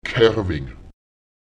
Lautsprecher kerwen [ÈkErwEN] sich verlaufen